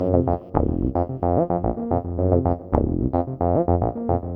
UR 303 acid bass 1 g.wav